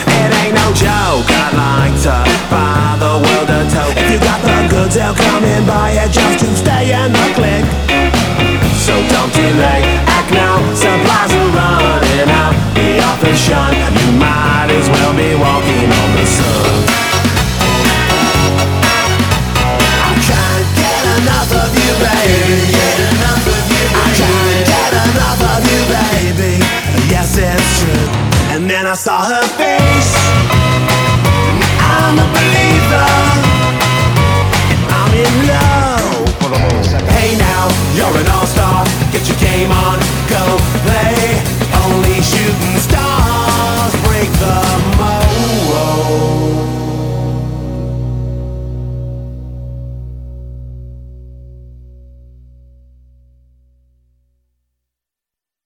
rock alternatif, ska et pop